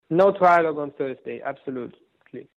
Un interviu cu europarlamentarul român din fracțiunea PPE.